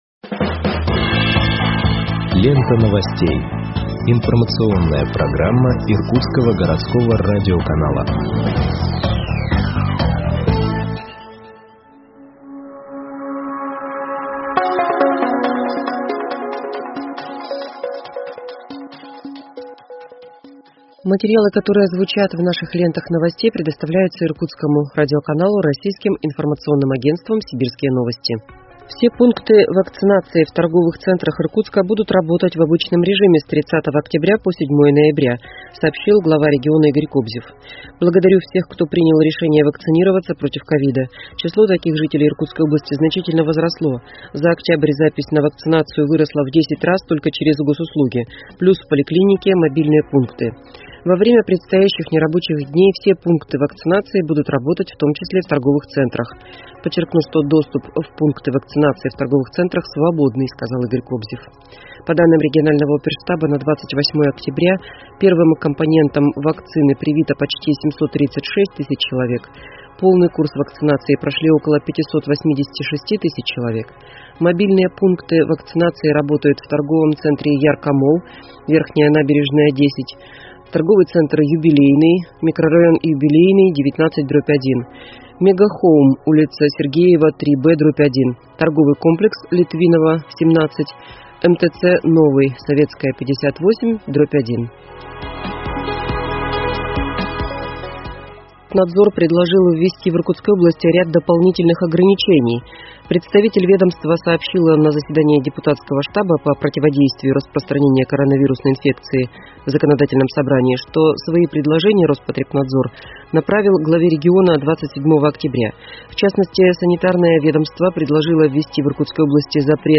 Выпуск новостей в подкастах газеты Иркутск от 29.10.2021 № 2